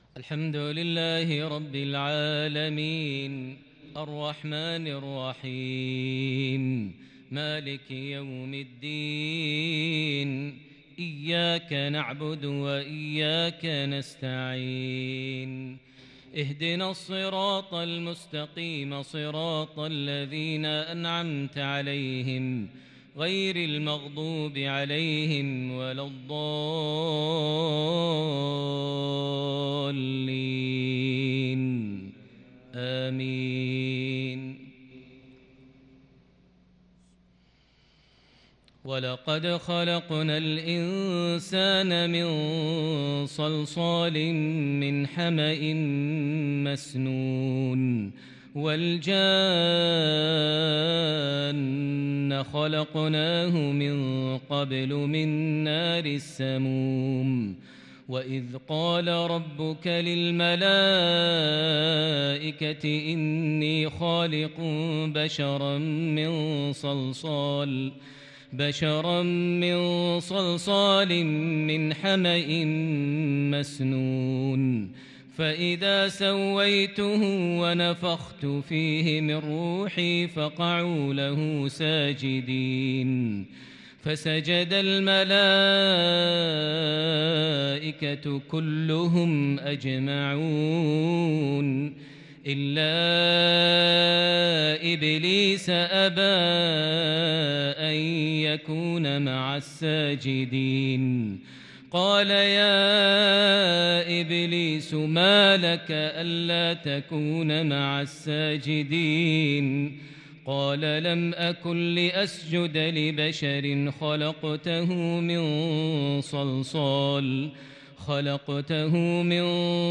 صلاة العشاء من سورة الحجر |الأحد 22 ذي الحجة 1442هـ | lsha 1-8-2021 prayer from Surah Al-Hijr 26- 50 > 1442 🕋 > الفروض - تلاوات الحرمين